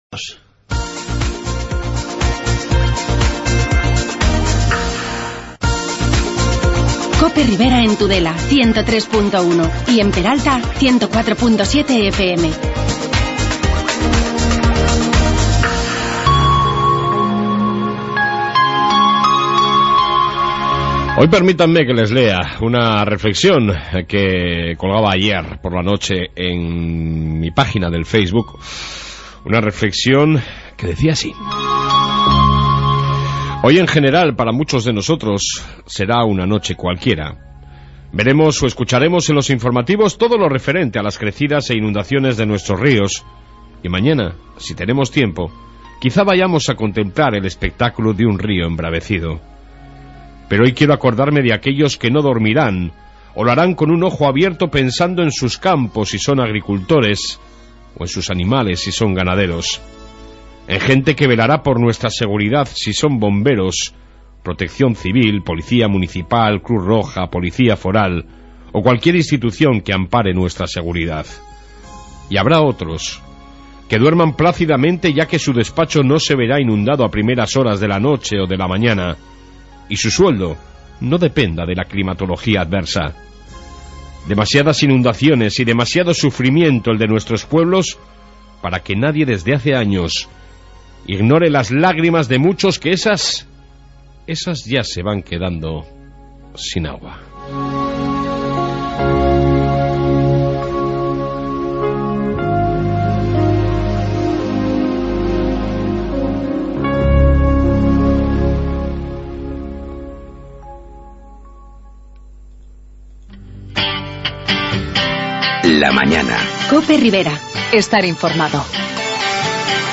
AUDIO: Especial inundaciones con entrevistas con Tudela, Funes, Milagro Y Buñuel...